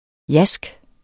Udtale [ ˈjasg ]